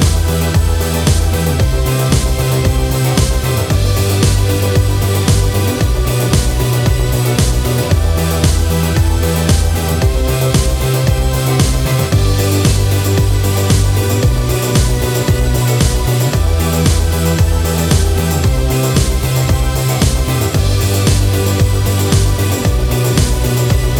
With Explicit Backing Vocals Rock 3:55 Buy £1.50